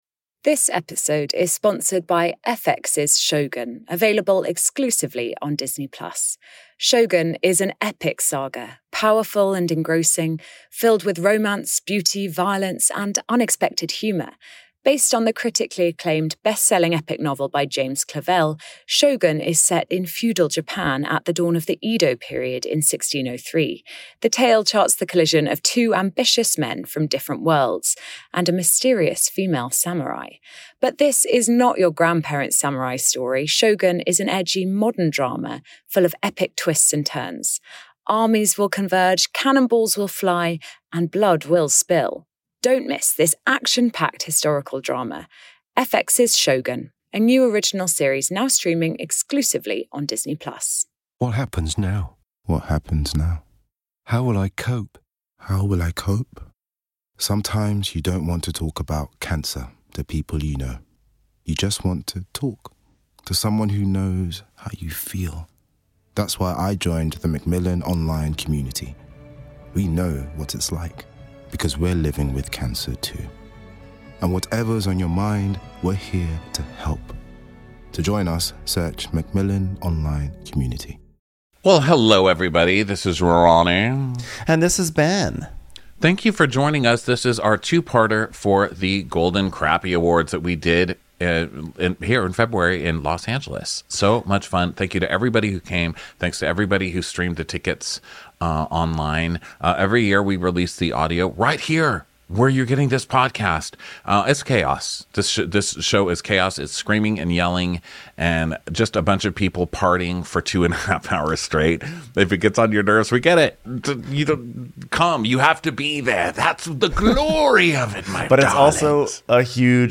Get your ballgowns on and your limos gassed up, because we’re honoring the best and worst that Bravo had to offer this year. Thanks to everyone who came, who streamed and who celebrated with us!